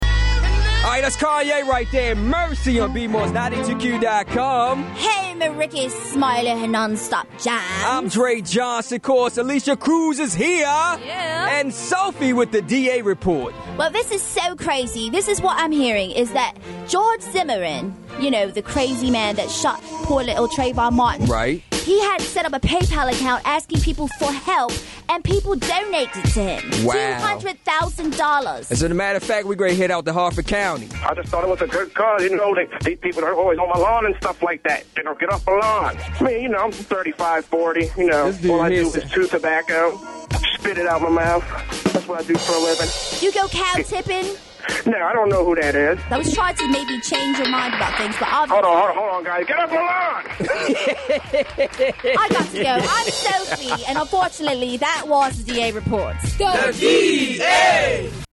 The DA talks to a few people that donated to the Zimmerman fundraiser